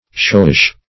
Showish \Show"ish\, a.